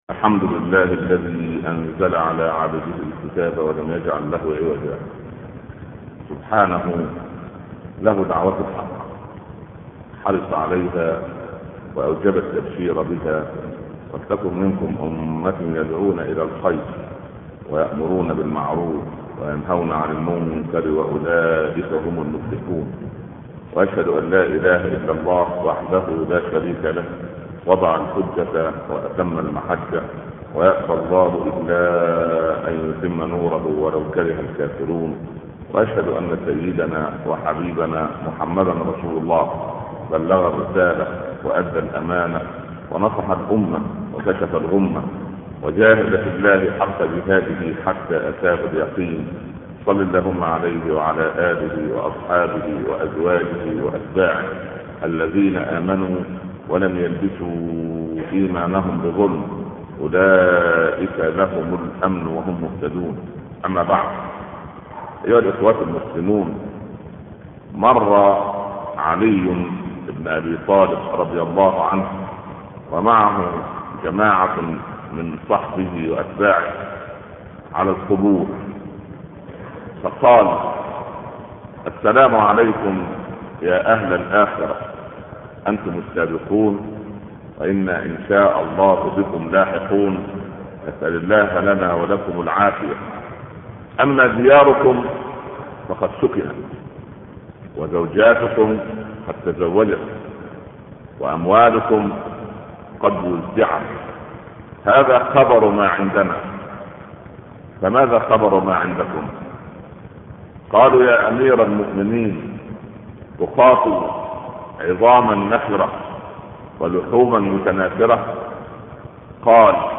تفاصيل المادة عنوان المادة موعظة الموت 20/05/2005 تاريخ التحميل الجمعة 8 نوفمبر 2013 مـ حجم المادة 10.86 ميجا بايت عدد الزيارات 711 زيارة عدد مرات الحفظ 293 مرة إستماع المادة حفظ المادة اضف تعليقك أرسل لصديق